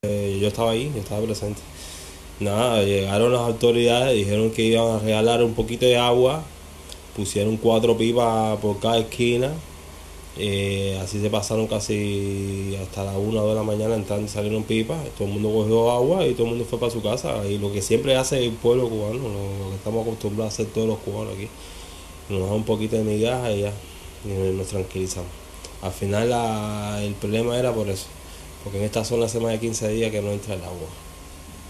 Testimonio